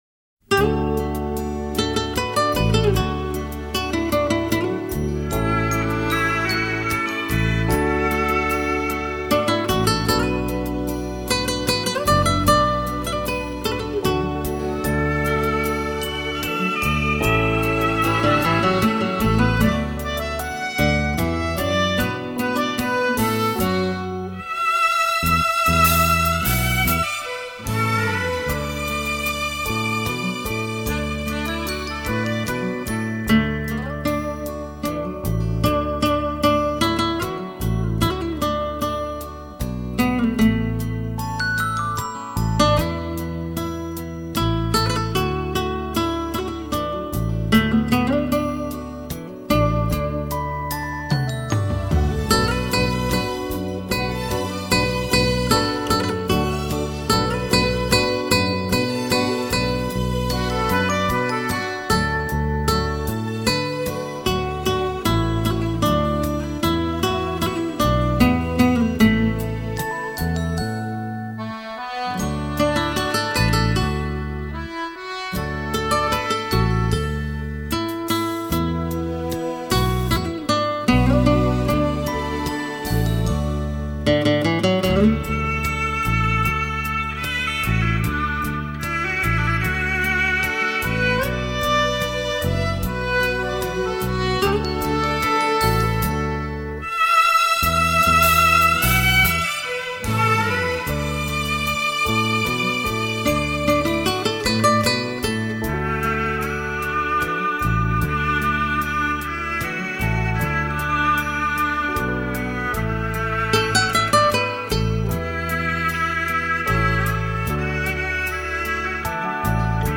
Hi Fi 发烧录音
吉他演奏